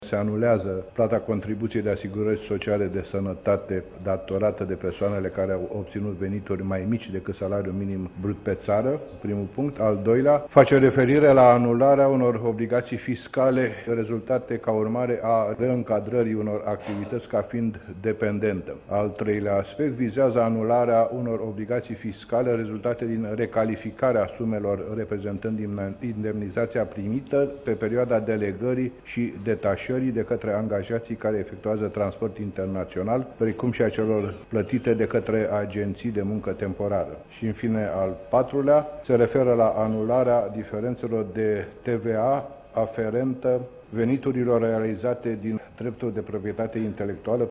Purtătorul de cuvânt al guvernului, Corneliu Calotă a explicat, în detaliu, ce cuprinde proiectul amnistiei.